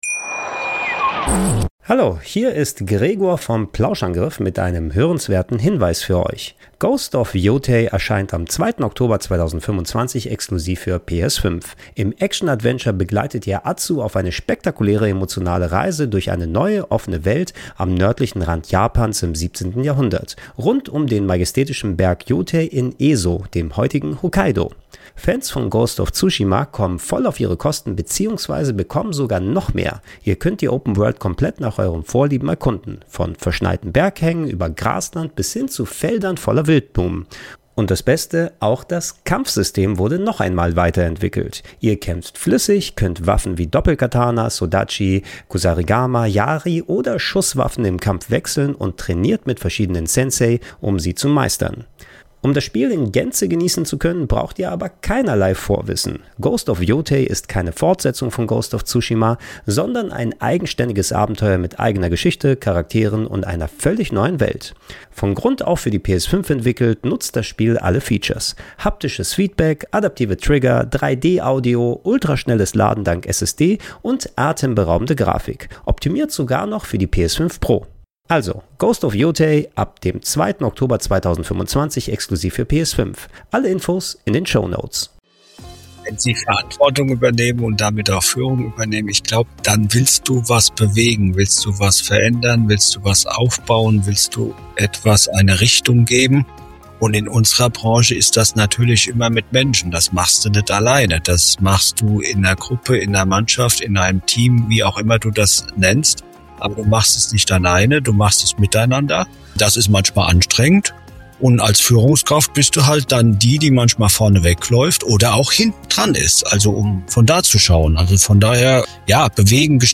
Doppelt hält besser? – Über Führung im Tandem und das Teilen von Verantwortung - Gespräch